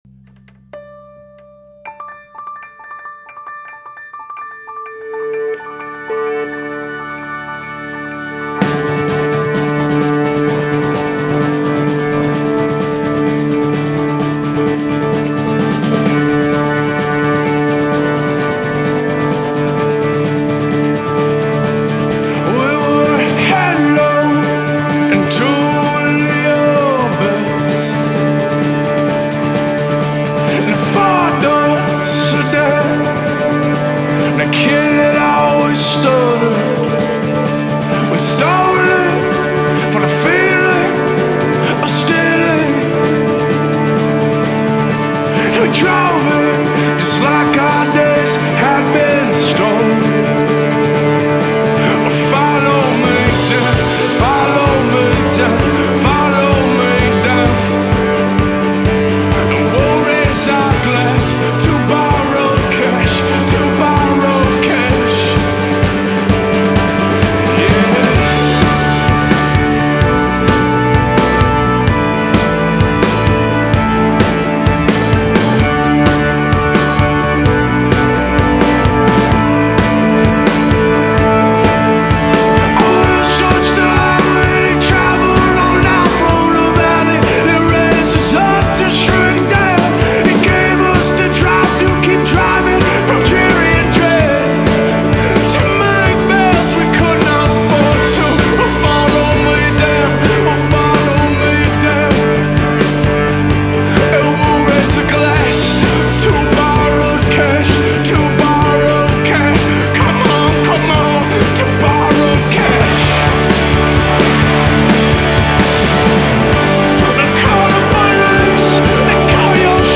mitreißenden tempowechseln